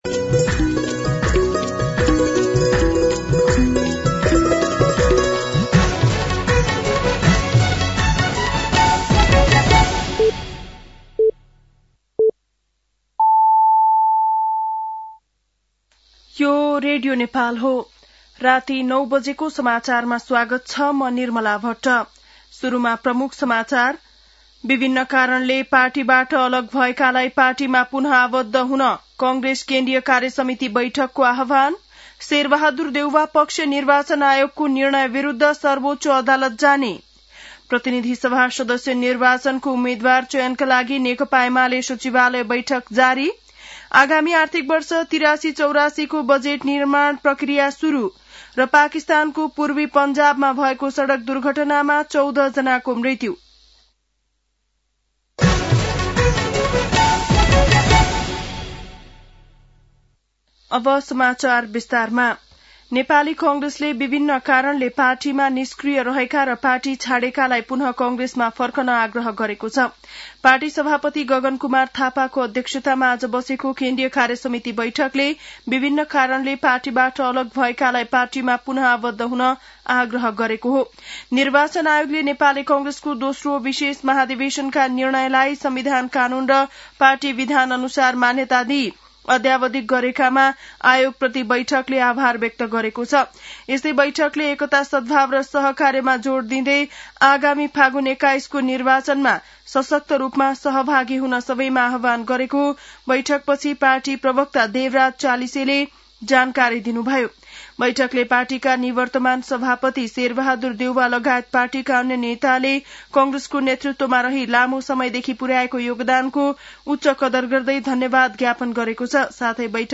बेलुकी ९ बजेको नेपाली समाचार : ४ माघ , २०८२
9-pm-nepali-news-1-1.mp3